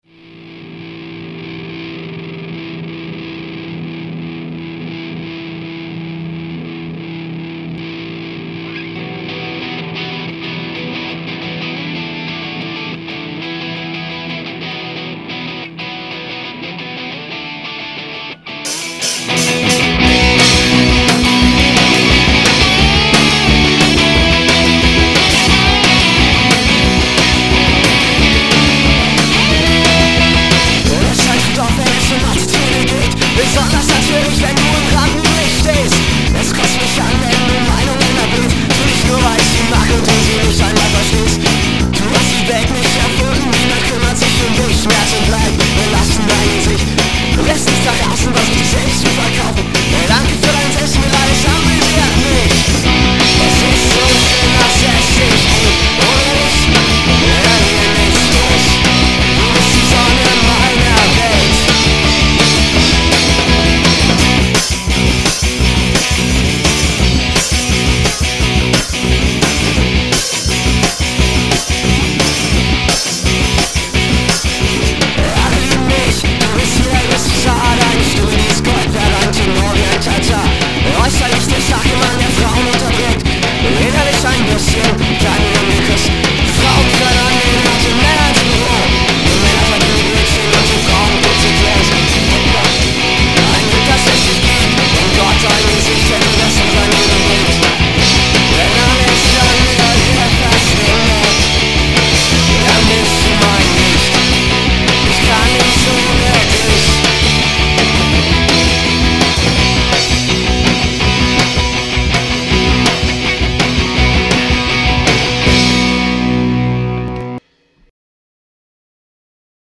E-Gitarre/Voc
Bass
Drums/Voc